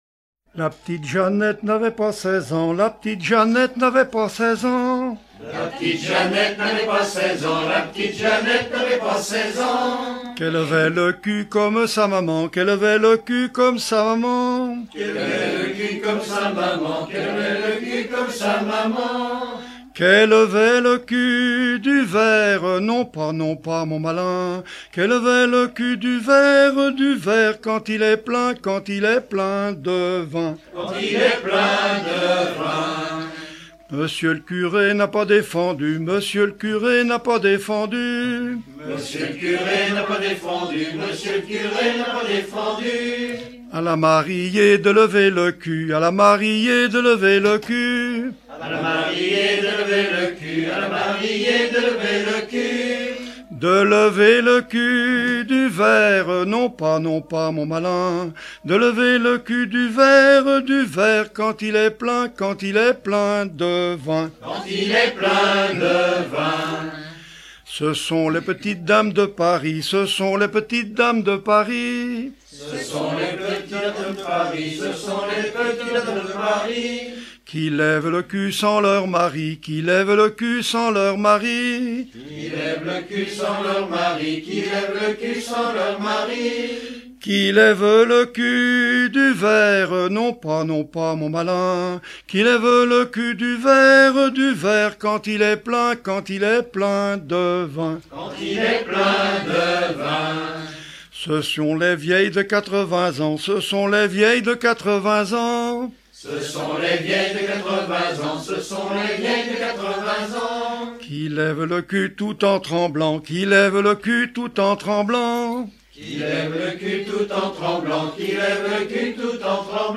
circonstance : bachique
Genre énumérative